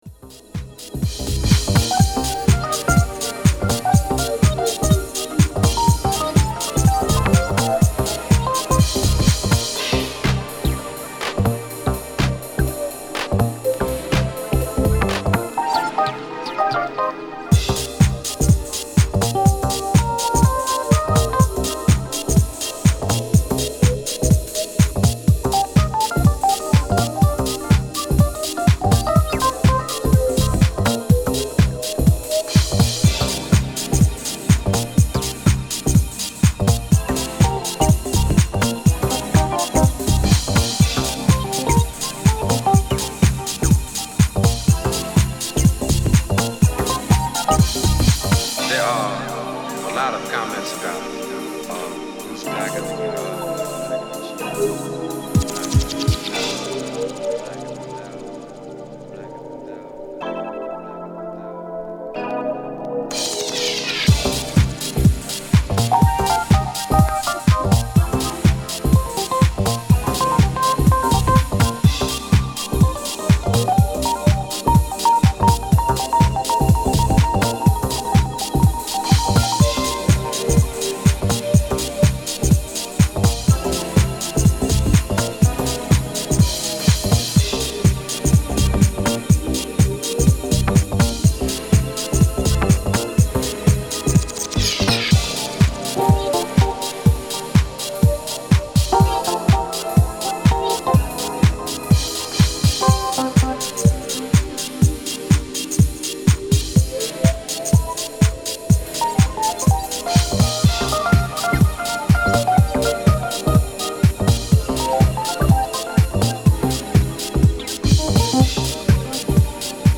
心地よく弧を描くようなシンセワークが魅力的です。モダンフュージョンのテイストも伺える、当店マスターピース。